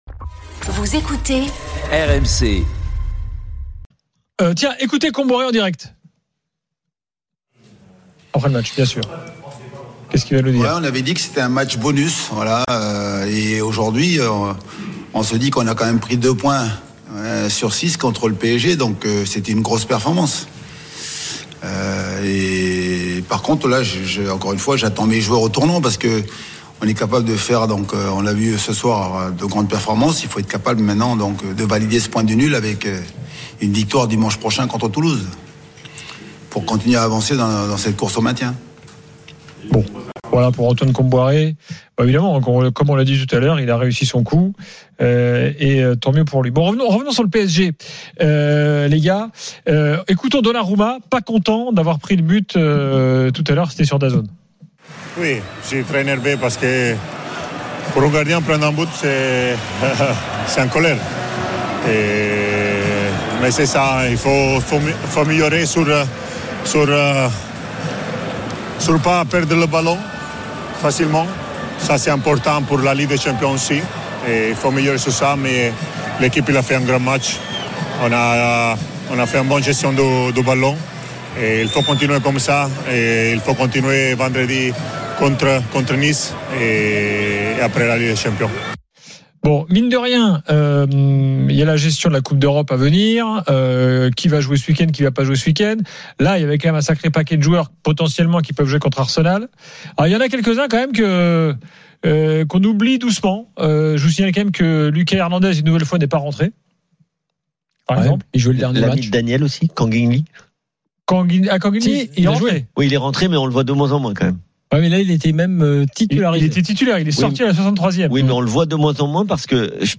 Les rencontres se prolongent tous les soirs avec Gilbert Brisbois, Daniel Riolo et Florent Gautreau avec les réactions des joueurs et entraîneurs, les conférences de presse d’après-match et les débats animés entre supporters, experts de l’After et auditeurs RMC.